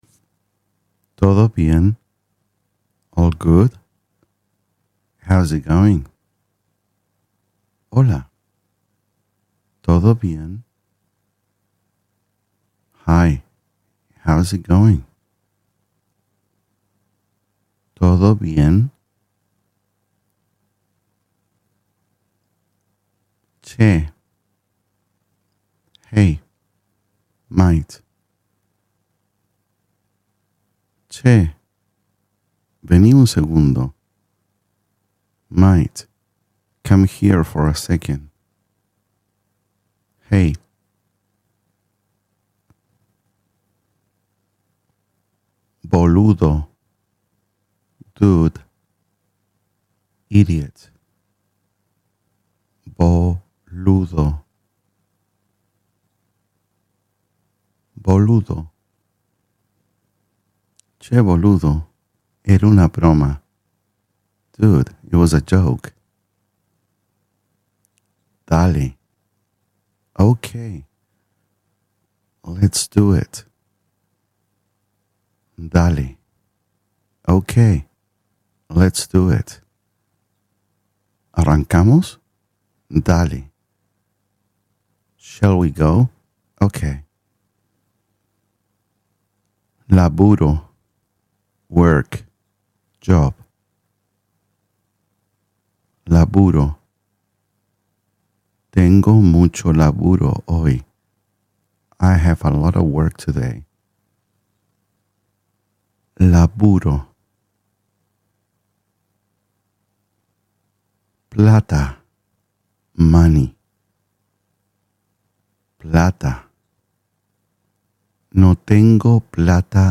Learn Argentinian Spanish Naturally While Driving — Real Daily Phrases